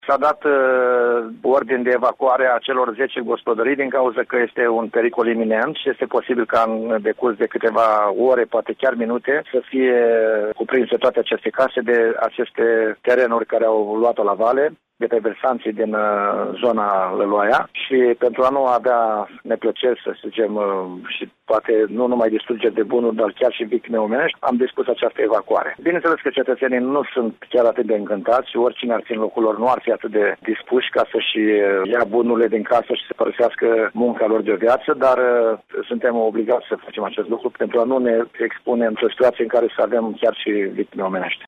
Primarul de Comănești, Viorel Miron, a declarat că astăzi s-a decis evacuarea populației din zonă.